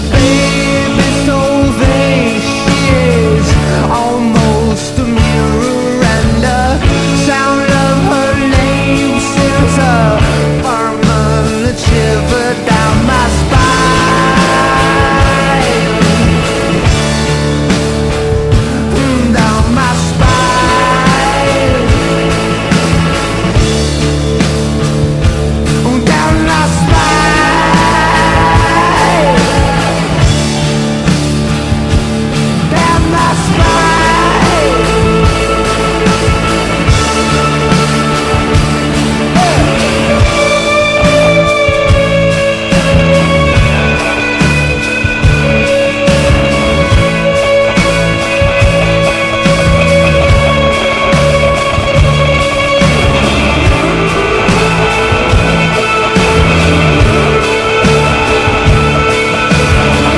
ROCK / 60'S / INSTRO / DRUM